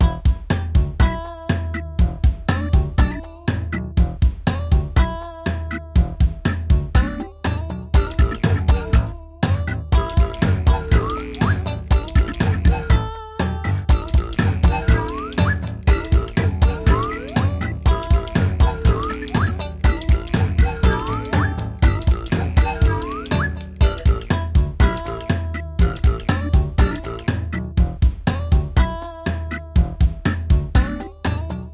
Rythm and Blues (bucle)
blues
repetitivo
sintetizador